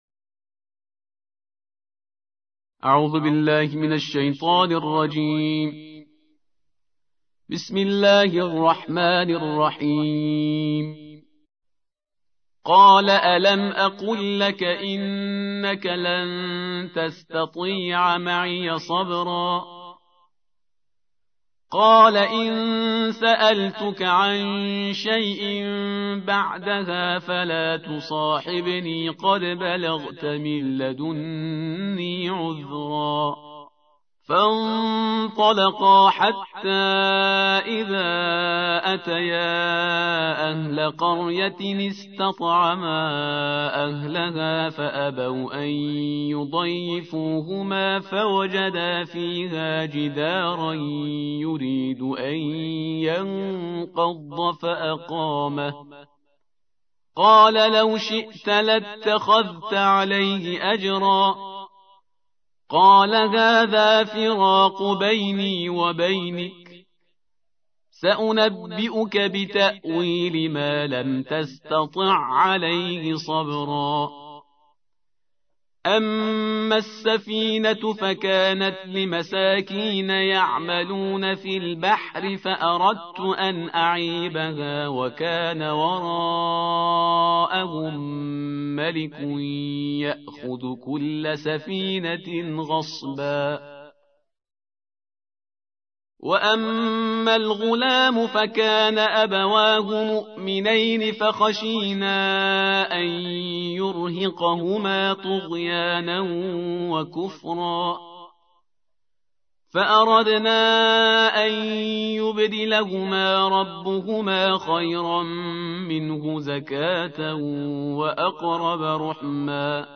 الجزء السادس عشر / القارئ